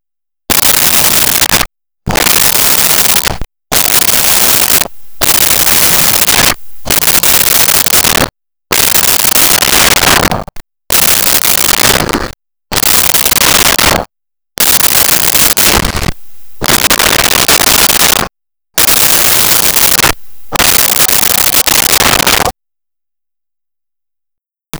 Breaths Synthesized
Breaths Synthesized.wav